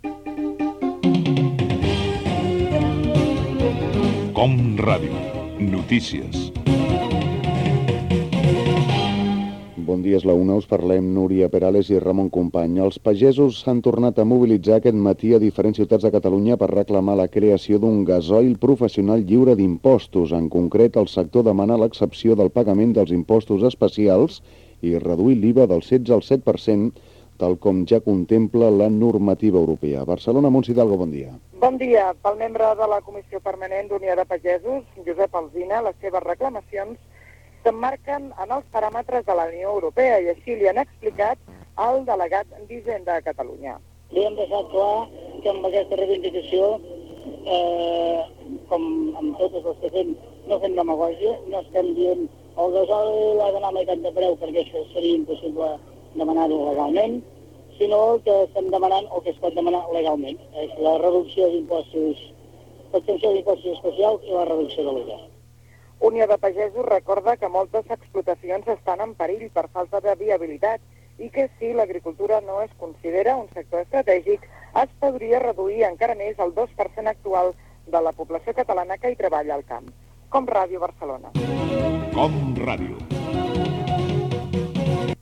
Careta del programa, informació sobre Unió de Pagesos, indicatiu
Informatiu
FM